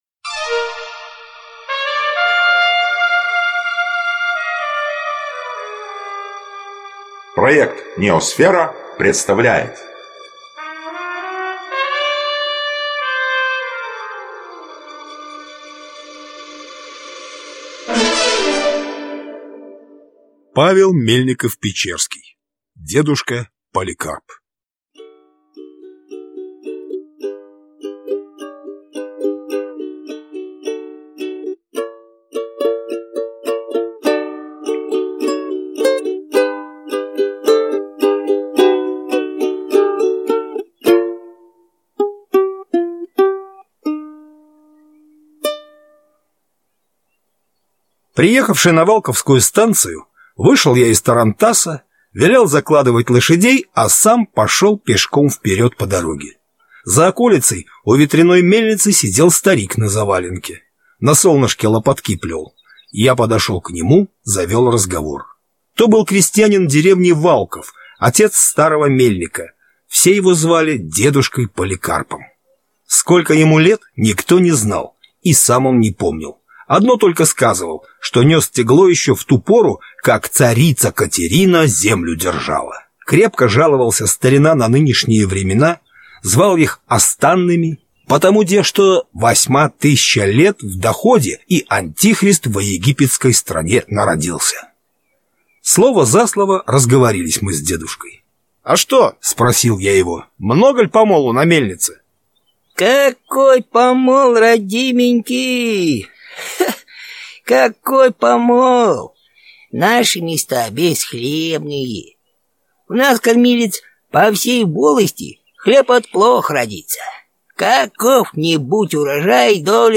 Аудиокнига Дедушка Поликарп | Библиотека аудиокниг